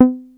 303 C4  3.wav